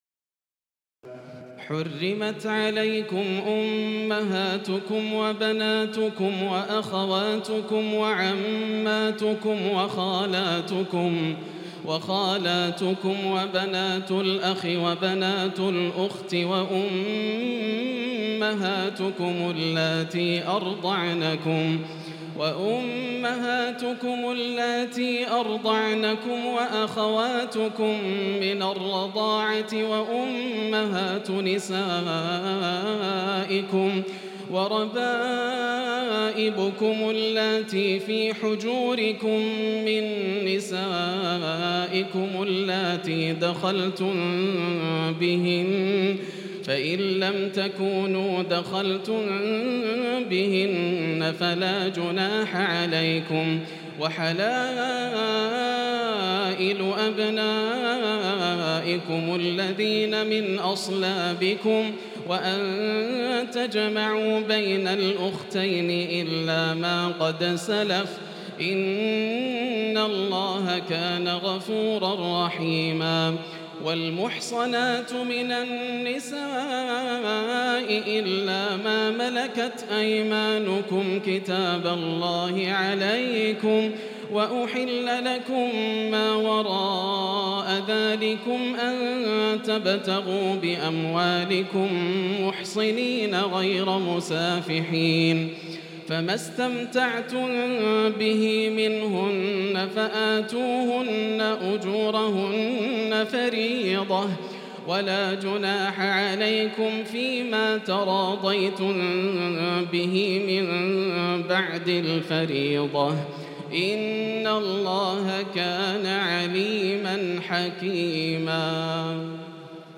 تراويح الليلة الرابعة رمضان 1438هـ من سورة النساء (23-87) Taraweeh 4 st night Ramadan 1438H from Surah An-Nisaa > تراويح الحرم المكي عام 1438 🕋 > التراويح - تلاوات الحرمين